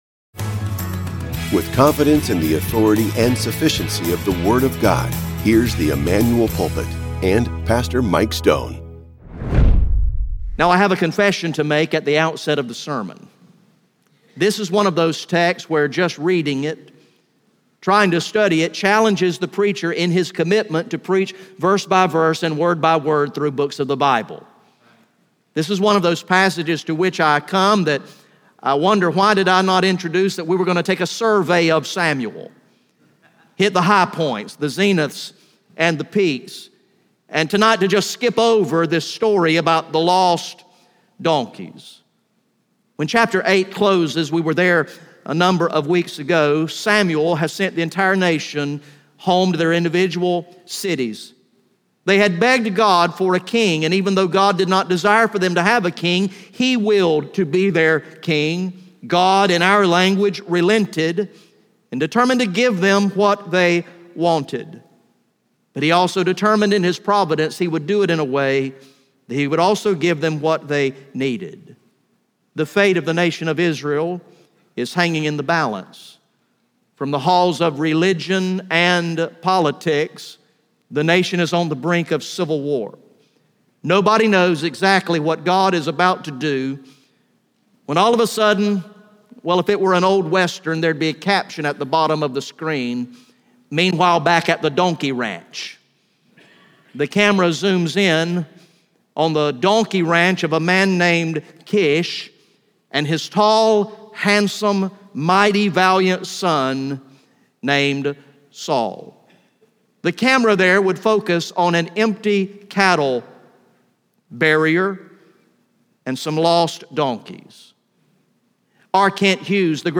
GA Message #15 from the sermon series titled “Long Live the King!